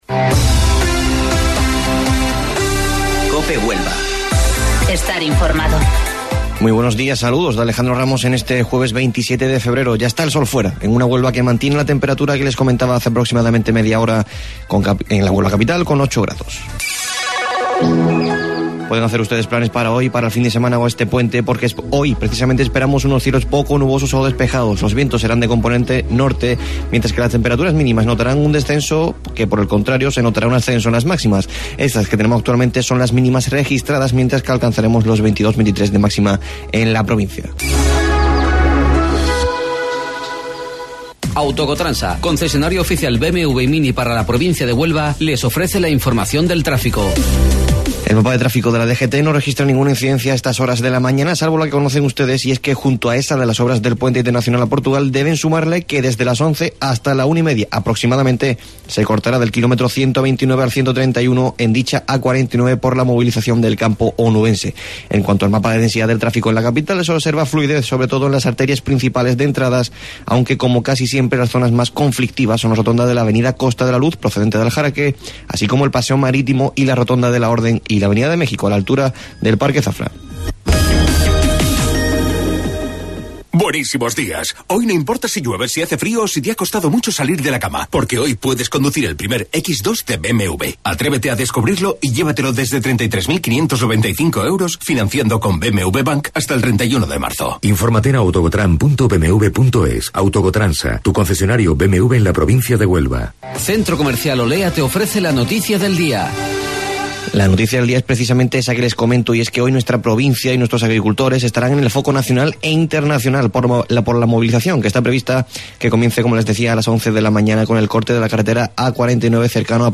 AUDIO: Informativo Local 08:25 del 27 Febrero